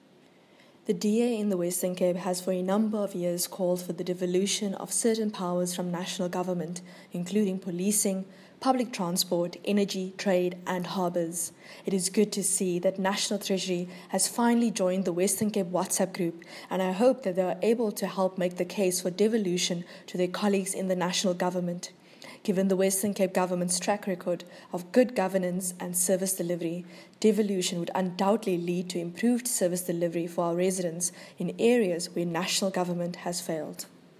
English soundbite from MPP Deidré Baartman attached.